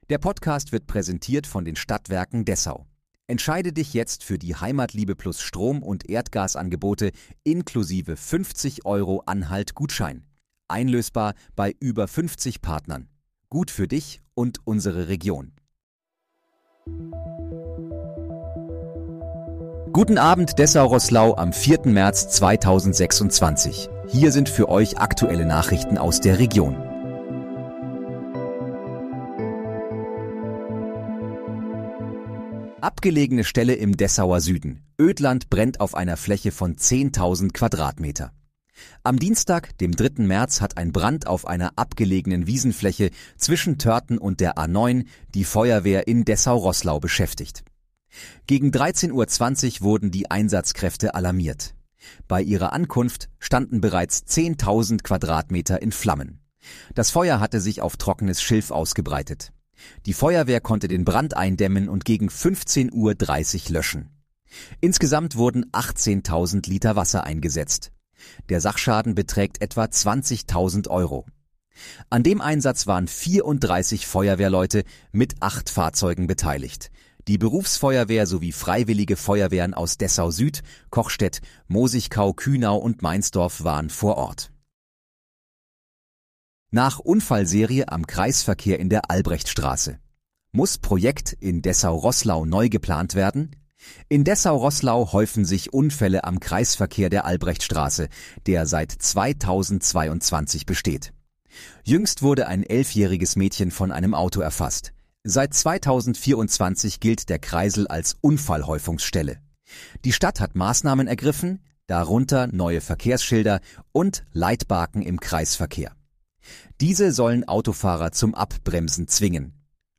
Guten Abend, Dessau-Roßlau: Aktuelle Nachrichten vom 04.03.2026, erstellt mit KI-Unterstützung